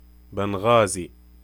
English: Name of the Libyan city Banghazi in standard Arabic (By a native speaker from Libya)